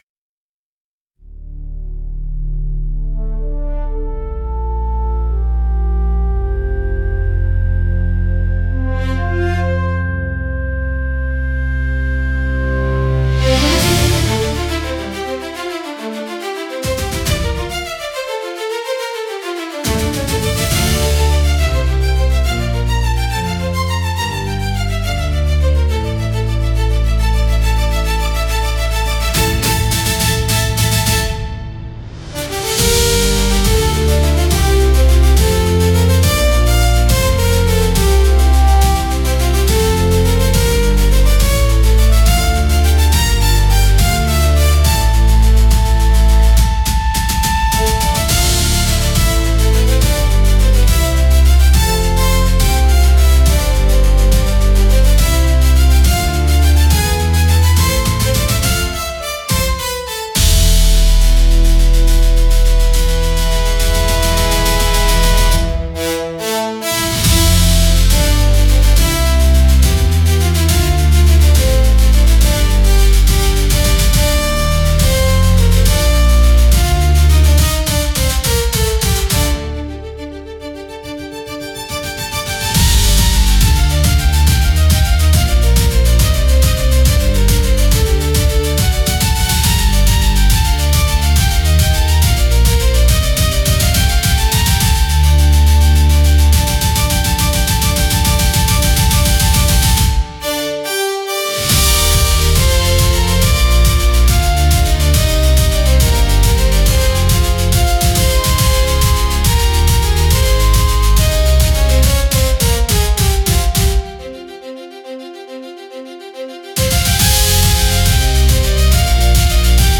優雅なストリングスと力強いブラスが重なり合い、広がるコーラスが新たな始まりの高揚感と希望をドラマチックに表現します。